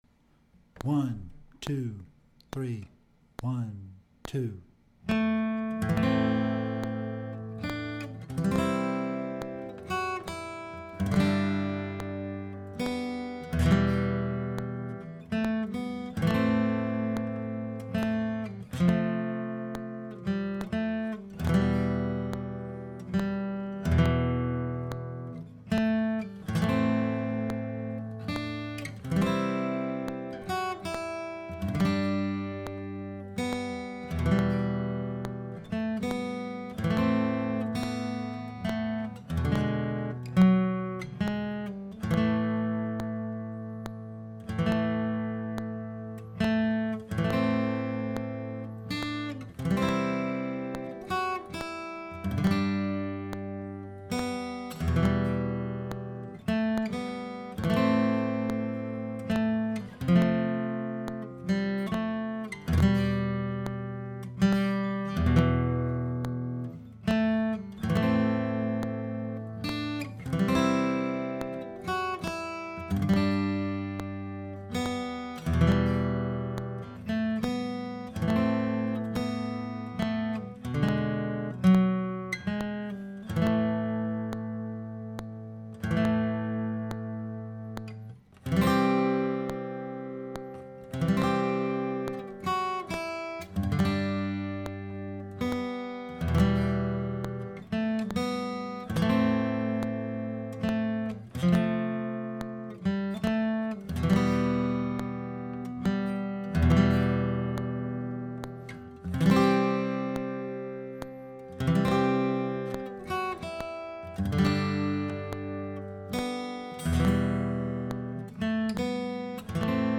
(intermediate solo guitar arrangement)
gsleeves_slow_int.mp3